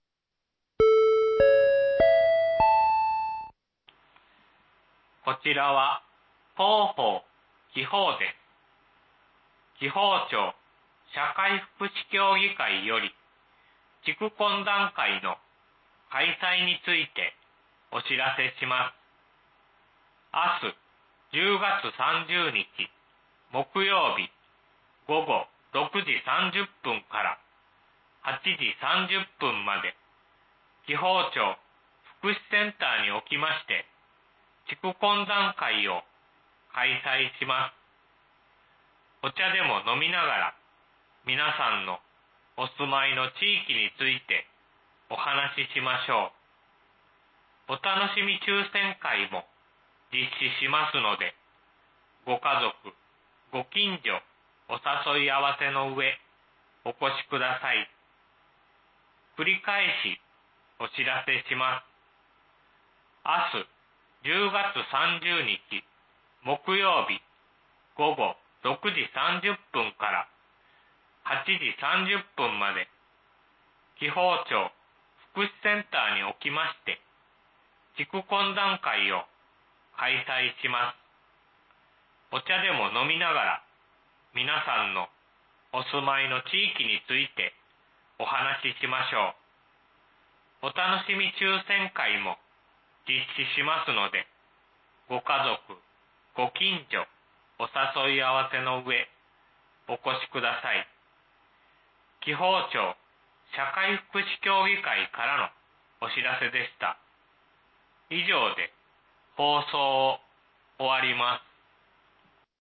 防災無線放送内容」カテゴリーアーカイブ
※鵜殿地区のみ放送です。